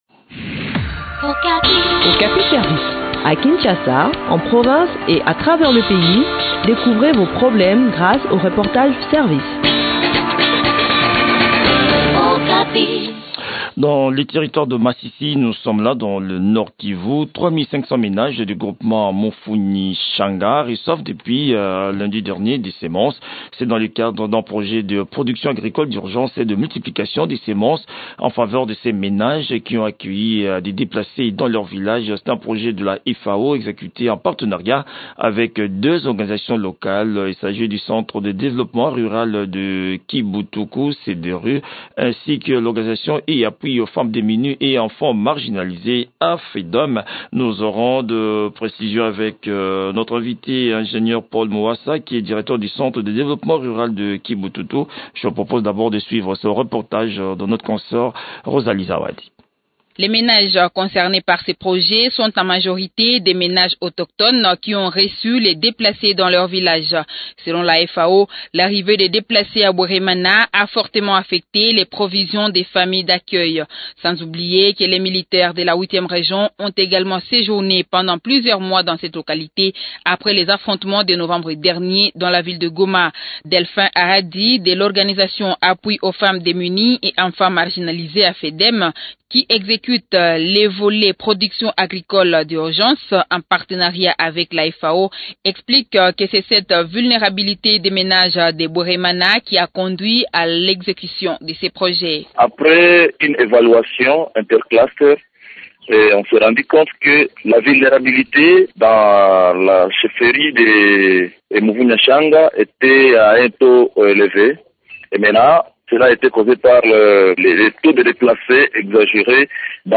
Le point sur l’exécution de ce projet dans cet entretien